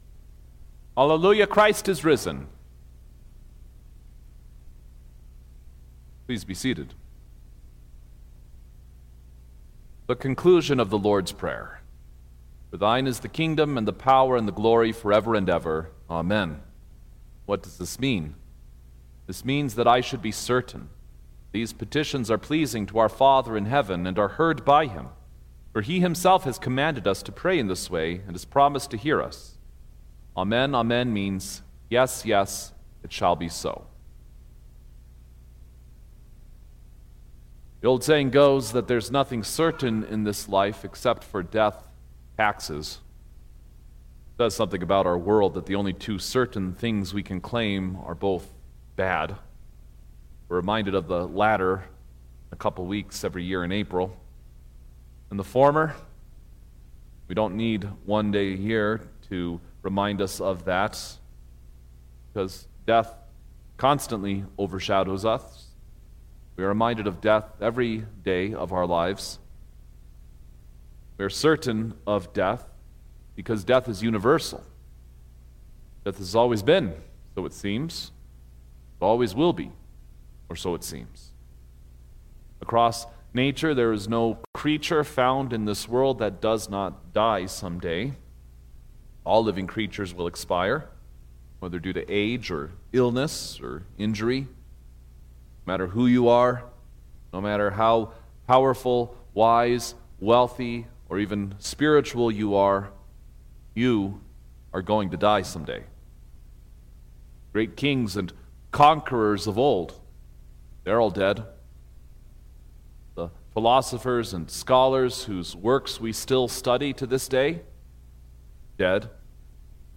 April-5_2026_Easter-Sunrise-Service_Sermon-Stereo.mp3